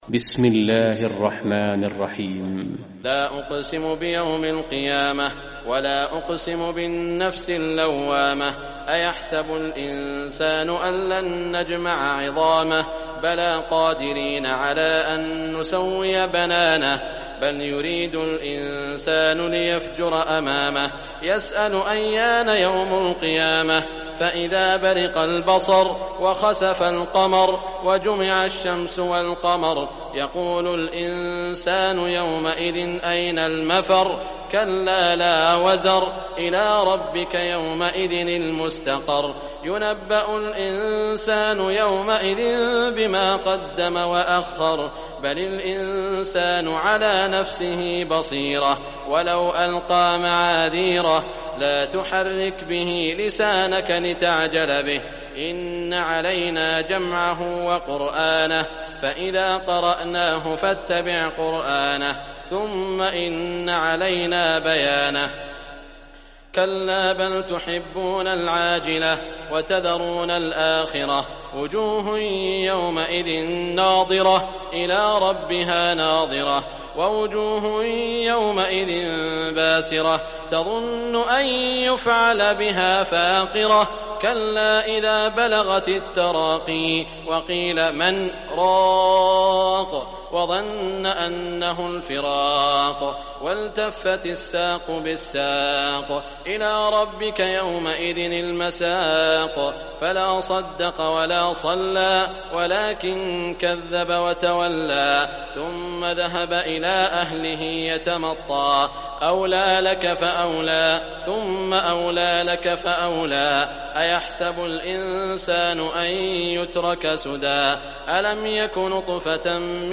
Surah Qiyamah Recitation by Sheikh Shuraim
Surah Qiyamah, listen or play online mp3 tilawat / recitation in Arabic in the beautiful voice of Sheikh Saud al Shuraim.